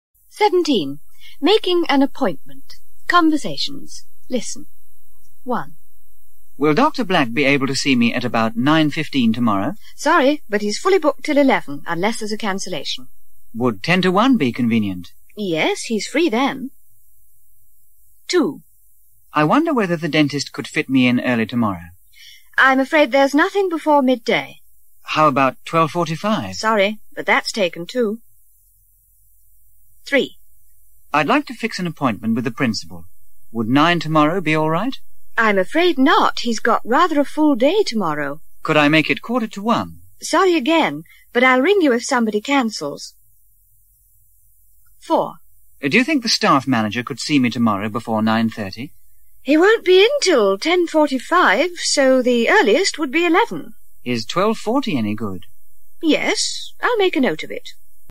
مجموعه مکالمات اجتماعی زبان انگلیسی – درس شماره هفدهم: قرار ملاقات